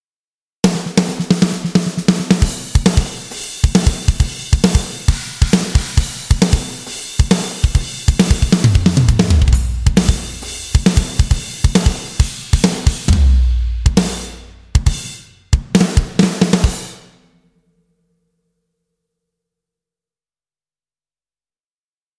Качество: Моно 48 кГц 24 бита
Описание: Ударная установка
Gigantic low-tuned drums cut through with massive impact, while shimmering cymbals bring energy and brilliance, adding waves of polish and sheen.
Только ударные #4